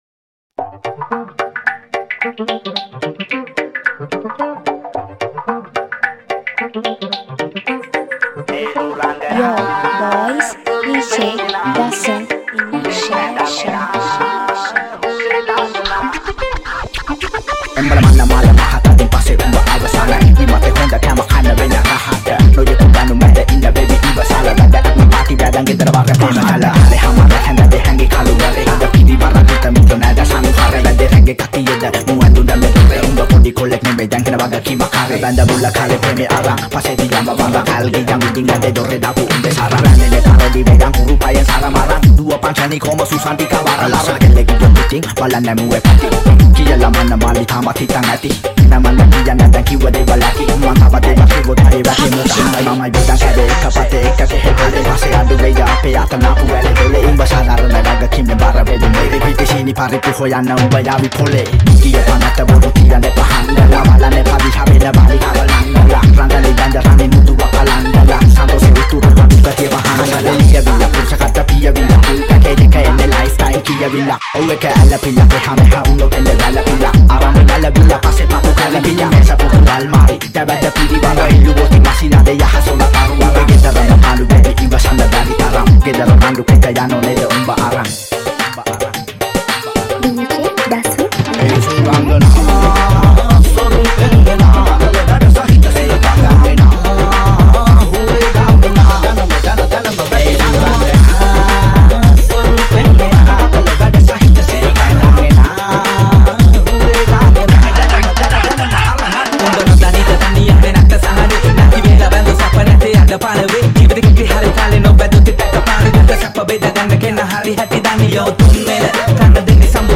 Punjab Style Dance Dj Remix